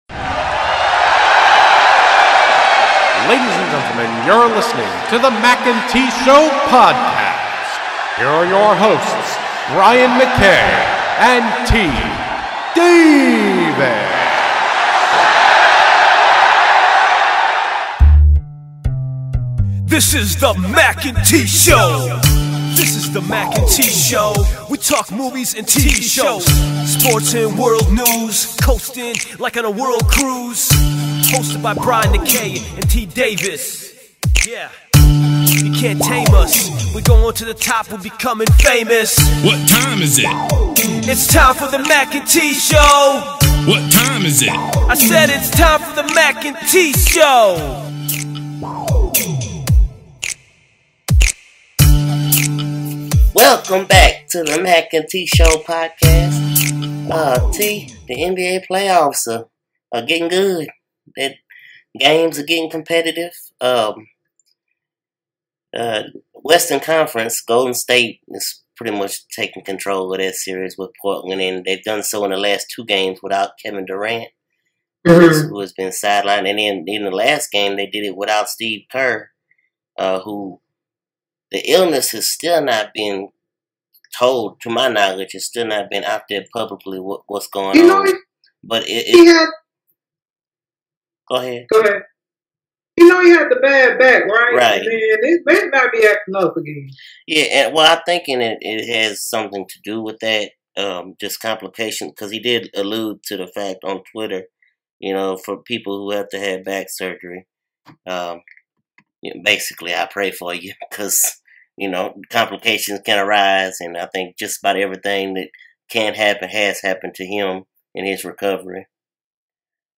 are your co-hosts
Ring Announcer Intro